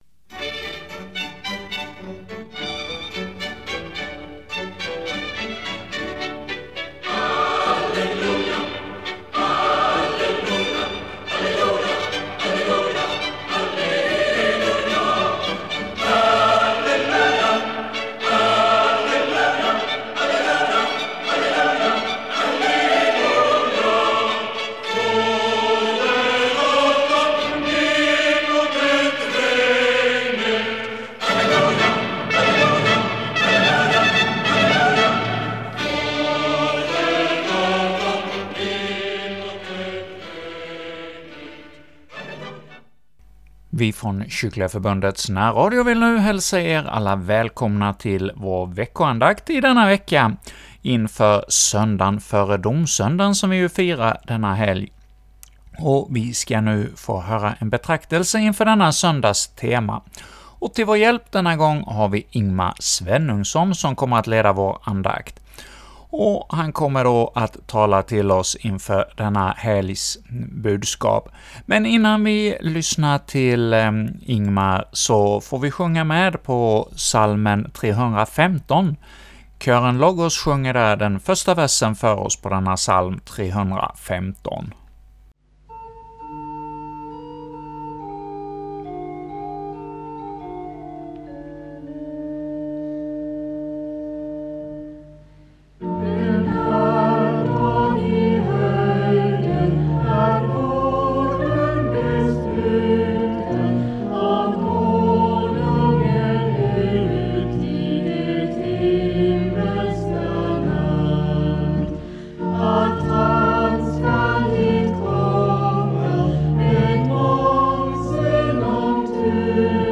leder andakt inför Söndagen före domsöndagen